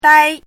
chinese-voice - 汉字语音库
dai1.mp3